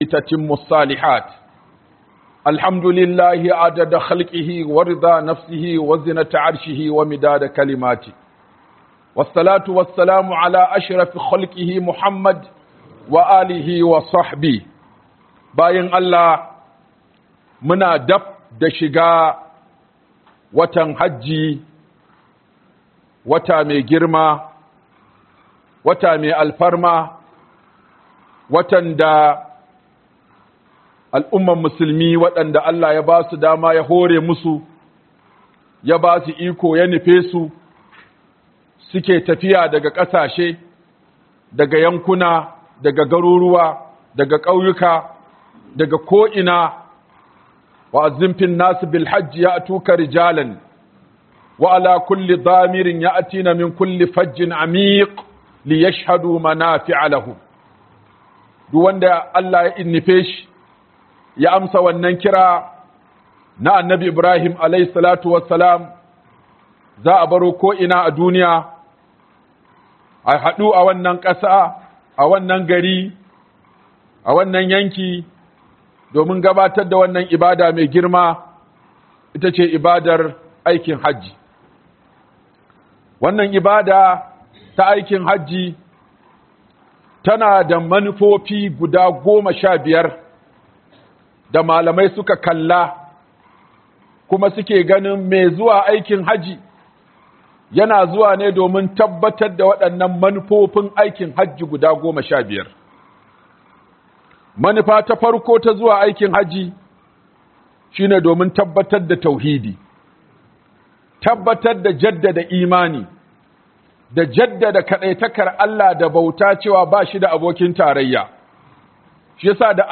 Fassarar Khutbah - Huduba
Fassarar Khutbah - Huduba by Sheikh Aminu Ibrahim Daurawa